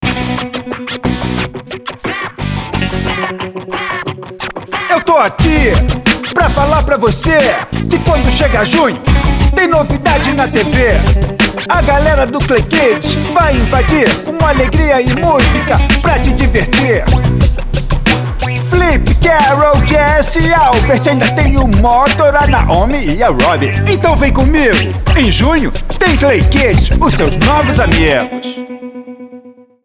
Masculino
• Tenho voz leve e versátil, e interpretação mais despojada.
• Tenho Home Studio.